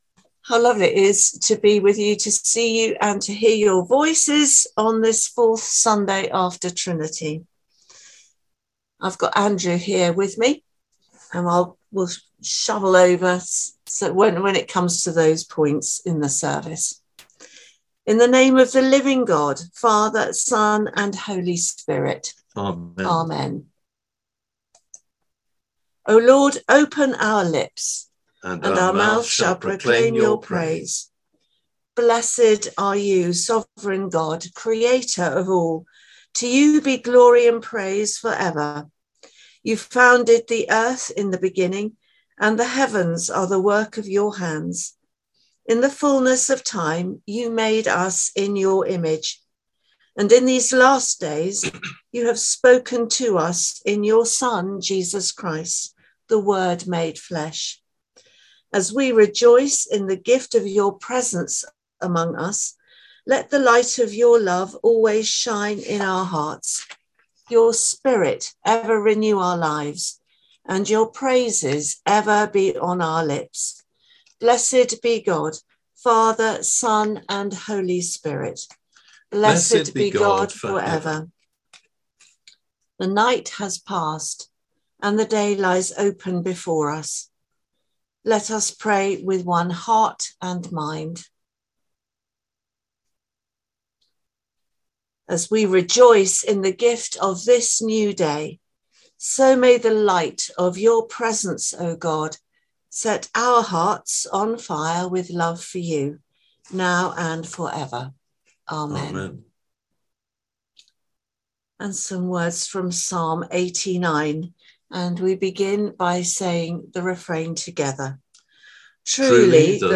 Morning Prayer - Sun, 21 May 2023